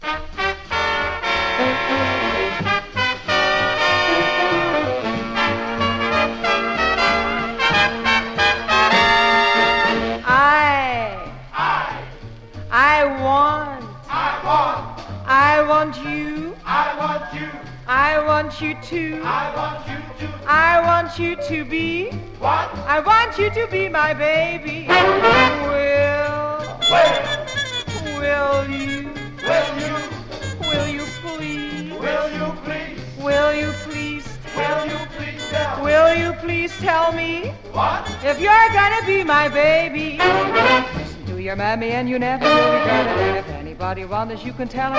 Jazz, Big Band　Netherlands　12inchレコード　33rpm　Mono